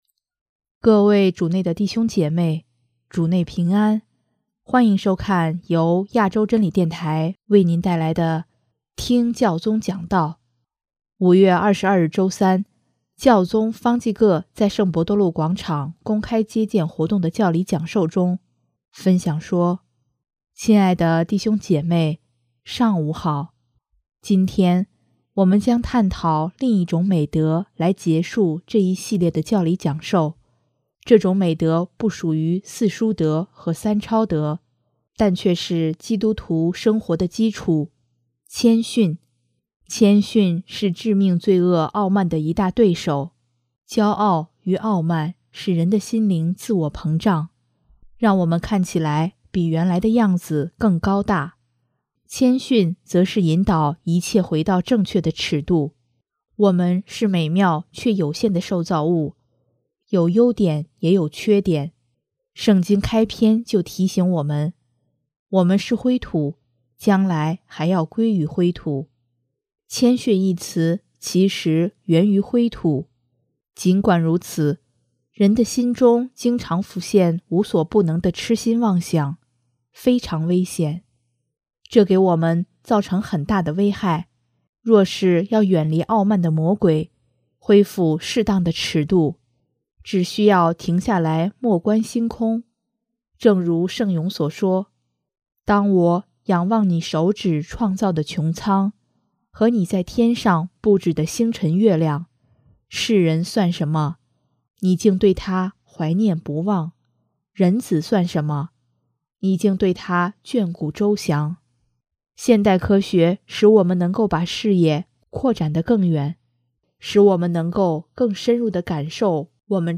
5月22日周三，教宗方济各在圣伯多禄广场公开接见活动的教理讲授中，分享说：